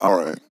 Alright Vocal Sample
Categories: Vocals Tags: Alright, english, LOFI VIBES, LYRICS, male, sample, wet
MAN-LYRICS-FILLS-120bpm-Am-7.wav